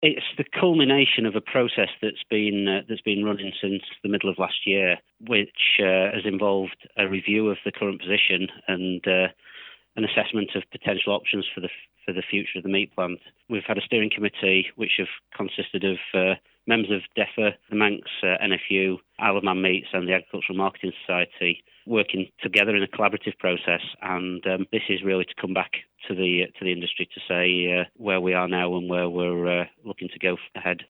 MHK Tim Baker is a member of the Department of Environment, Food and Agriculture - he says the plant is a vital piece of infrastructure: